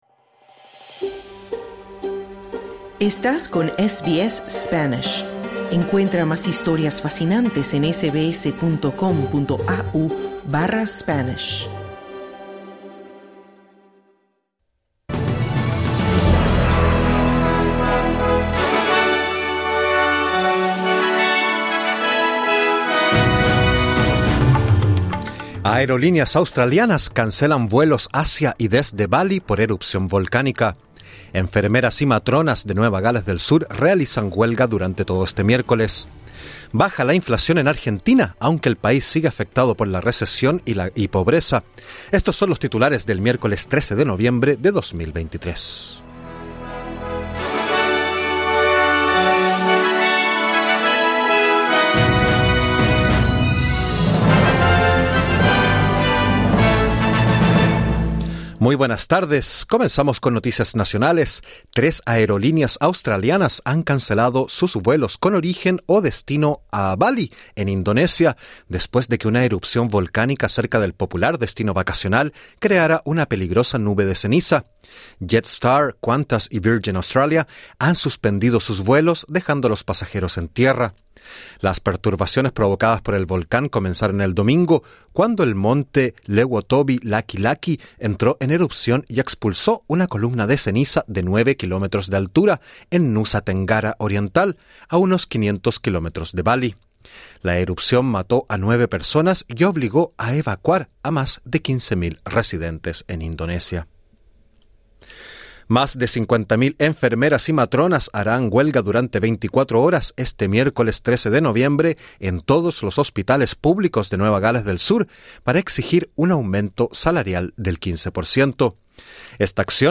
Escucha el boletín en el podcast localizado en la parte superior de esta página.